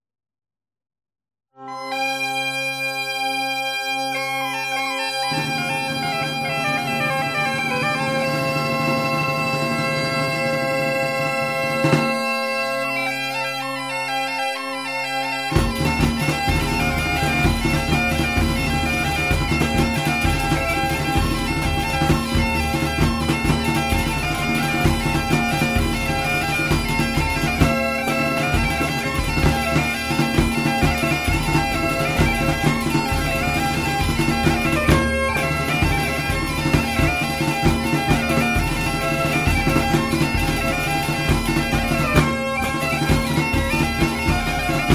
Tradicional